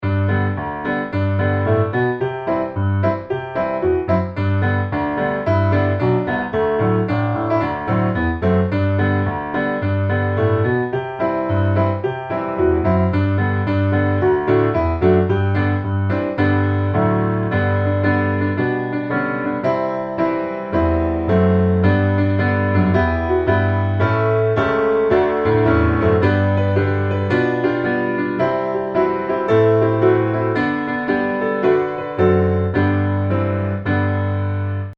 G Majeur